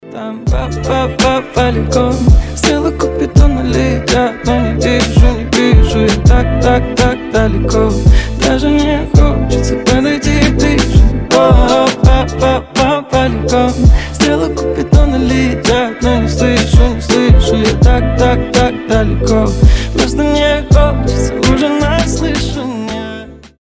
поп
rnb